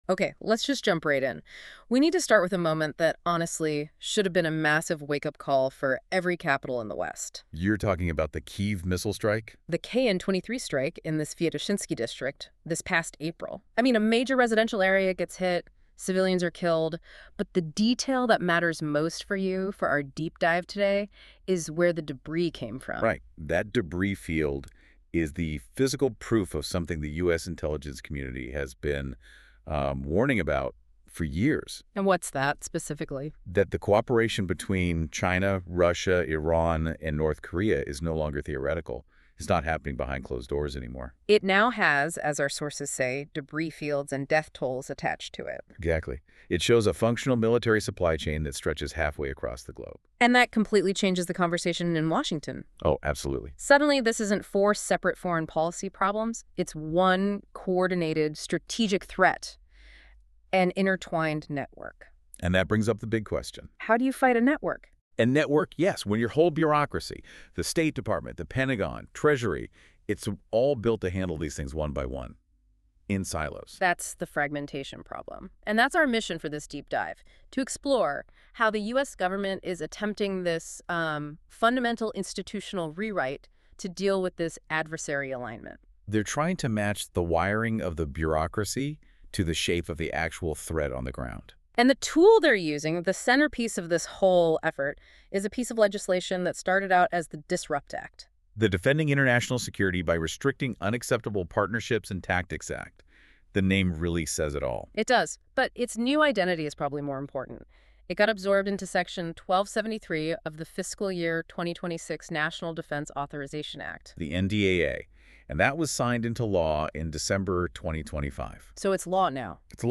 Related Podcasts These podcast episodes are produced with AI voice technology.